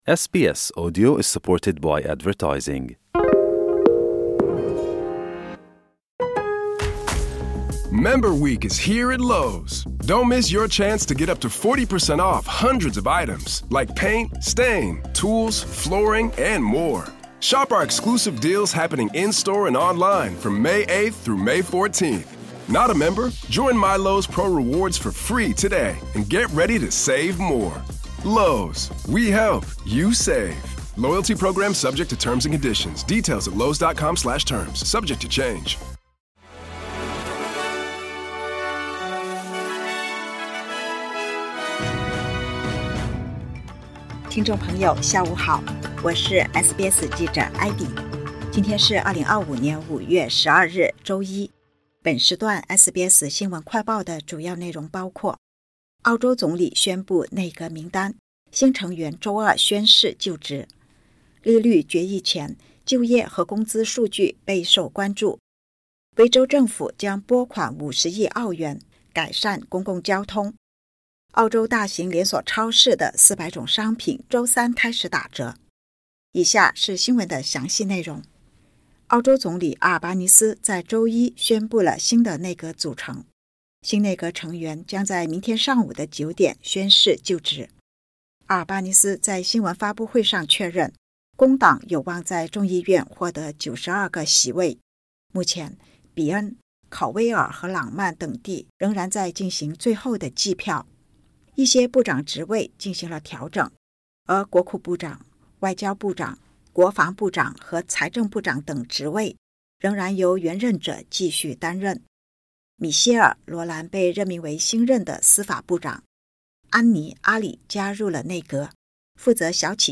【SBS新闻快报】澳洲总理宣布内阁名单 成员周二将宣誓就职